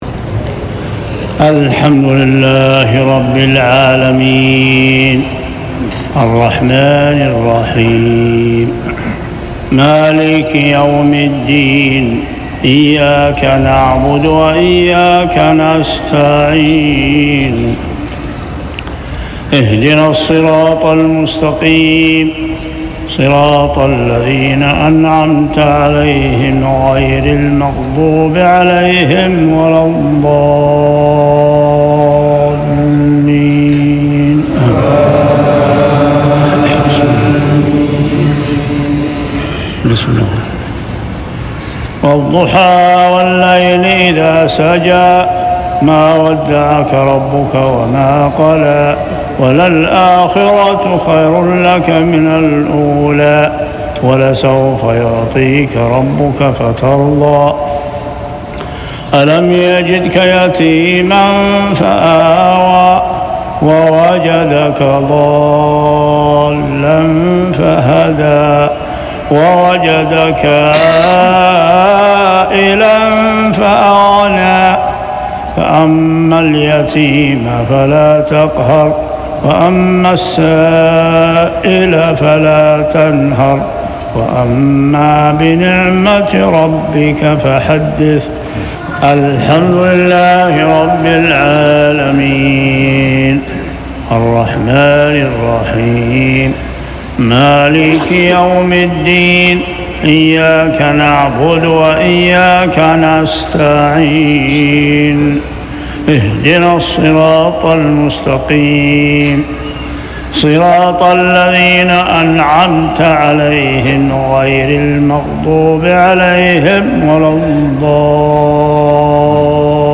صلاة المغرب عام 1406هـ سورتي الضحى و الشرح كاملة | Maghreb prayer surah Ad-Duhaa and Ash-sharh > 1406 🕋 > الفروض - تلاوات الحرمين